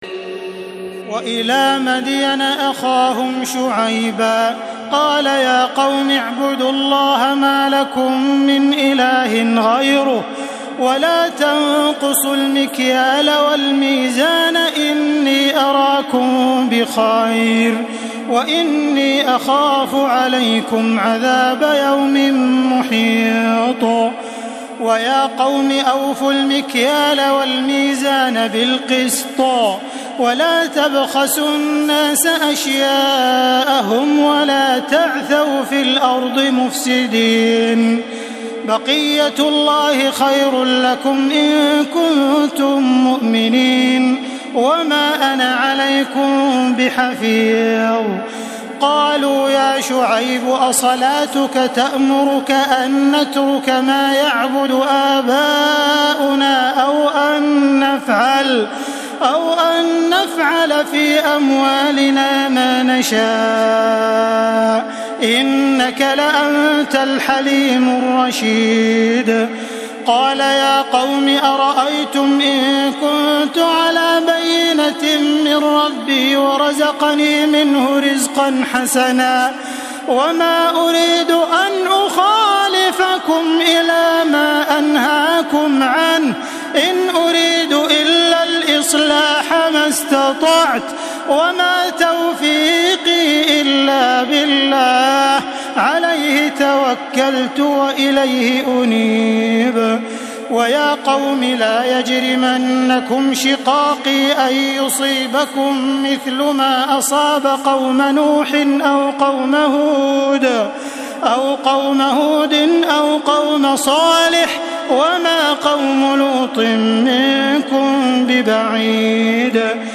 تراويح الليلة الحادية عشر رمضان 1432هـ من سورتي هود (84-123) و يوسف (1-53) Taraweeh 11 st night Ramadan 1432H from Surah Hud and Yusuf > تراويح الحرم المكي عام 1432 🕋 > التراويح - تلاوات الحرمين